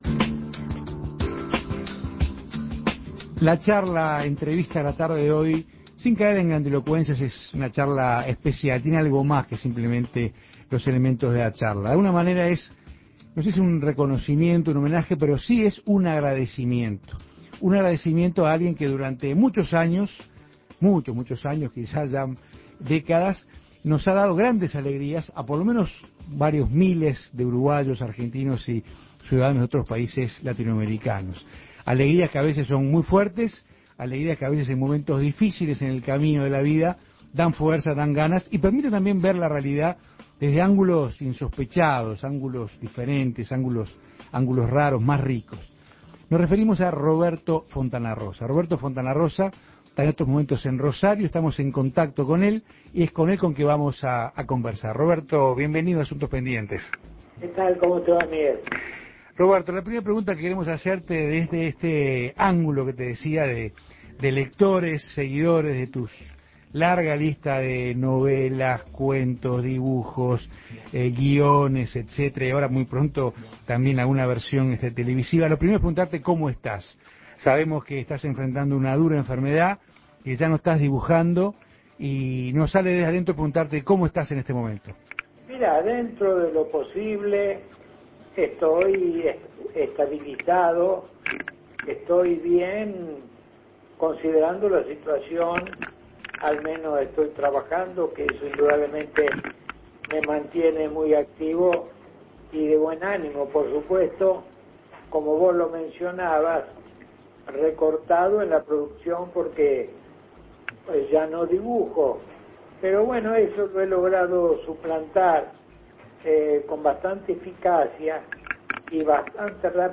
Escuche la entrevista a Roberto Fontanarrosa
Ante su reciente fallecimiento, Asuntos Pendientes recuerda la última entrevista que se le hizo en el programa, el pasado 2 de marzo.